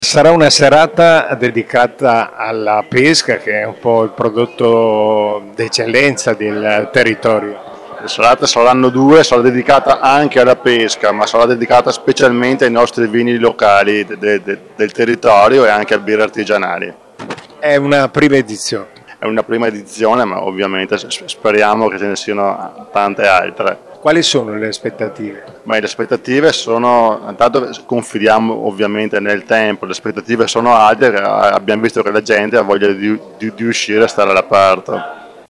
A parlare dell’evento, al microfono del nostro corrispondente
il vicesindaco e assessore all’Agricoltura di Bussolengo Giovanni Amantia